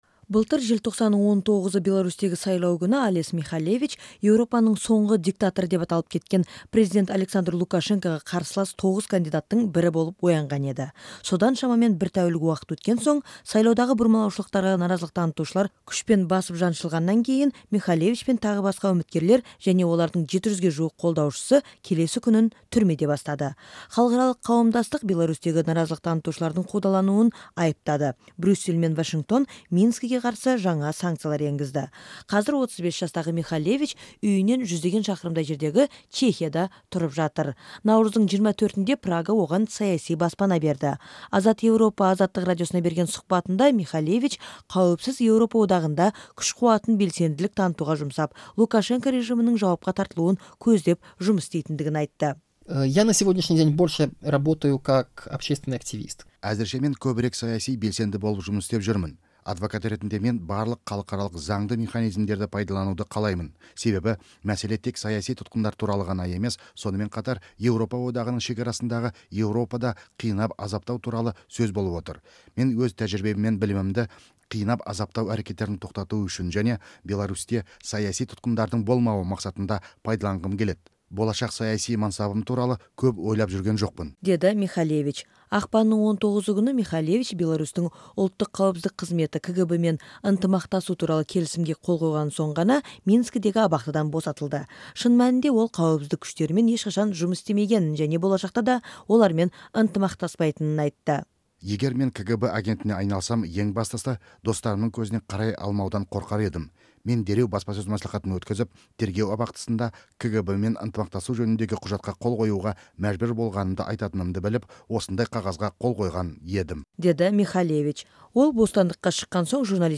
Алесь Михалевичтің Азаттыққа берген сұқбатын тыңдаңыз